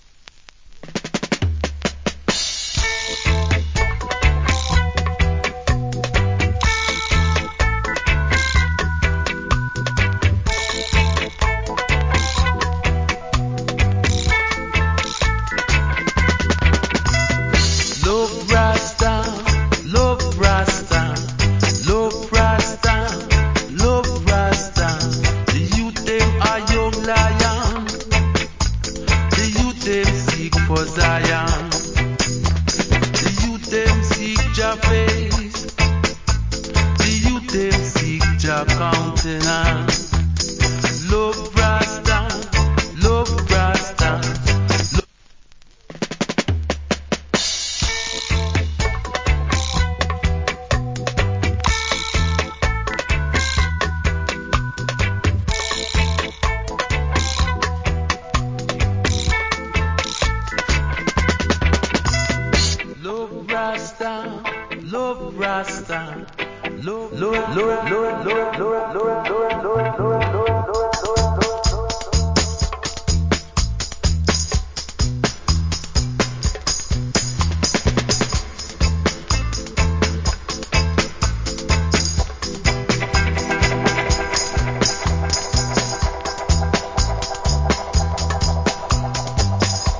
Wicked Roots Rock Vocal. / Good Dub.